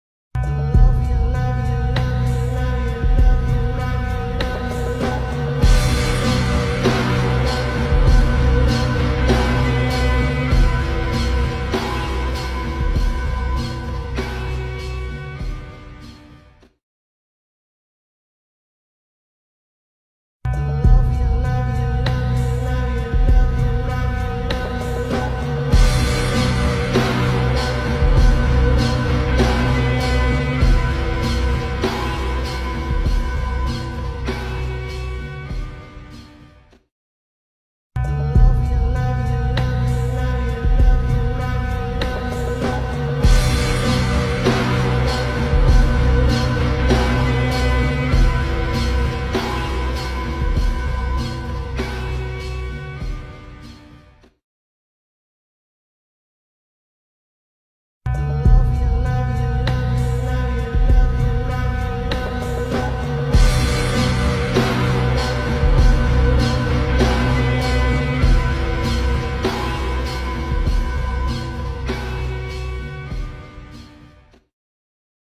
در حالت Slowed با ریتمی کند شده